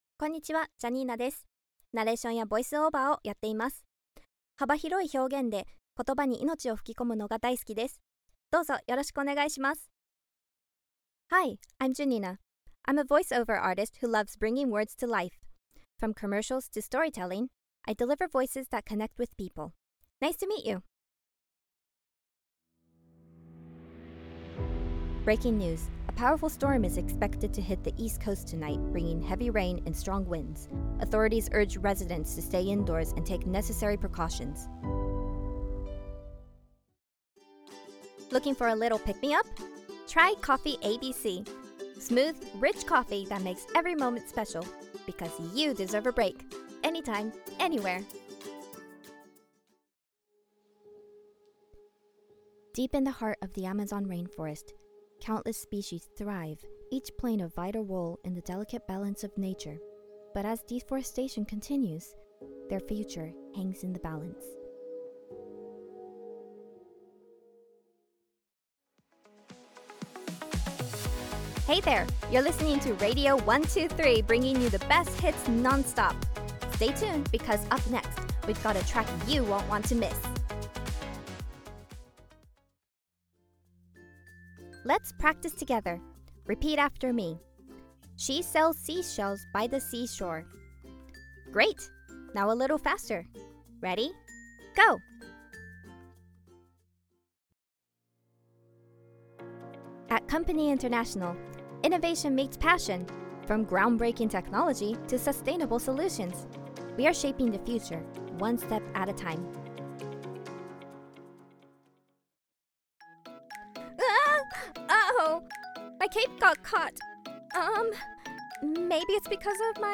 性別 女性
アメリカ英語